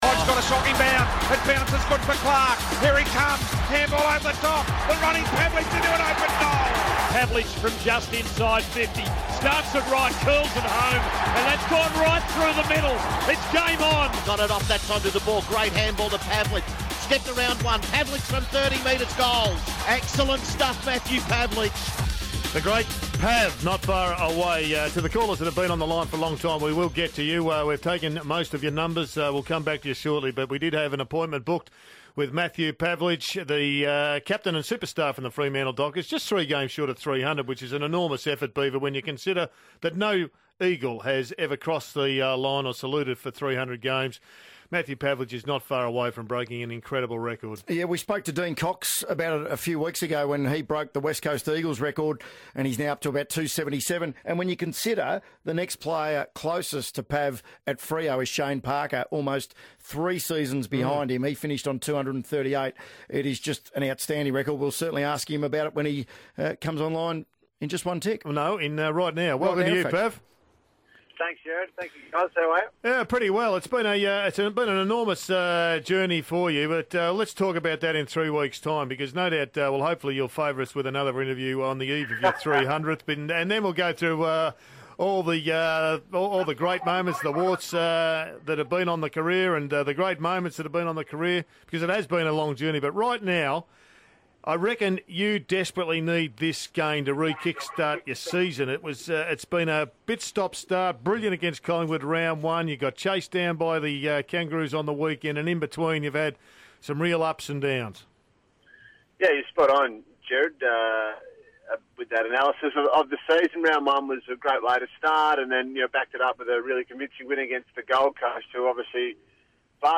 Matthew Pavlich talks to 3AW about a possible future outside of football